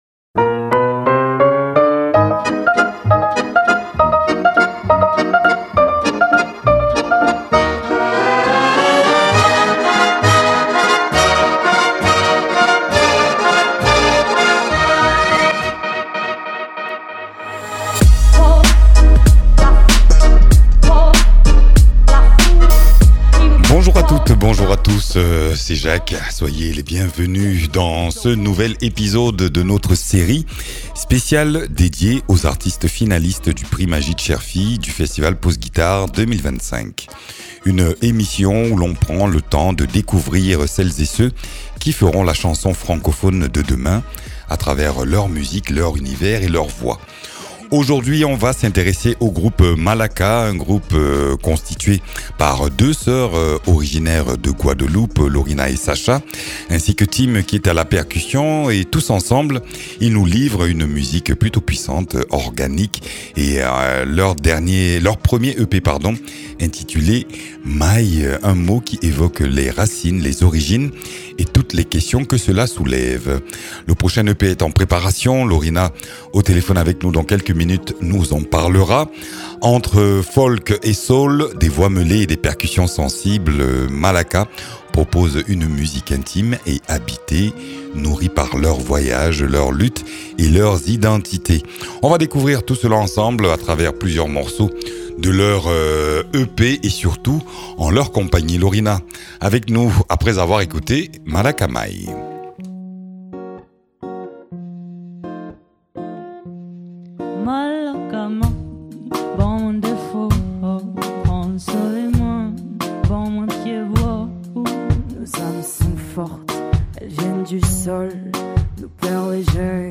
musicienne, autrice-compositrice-interprète.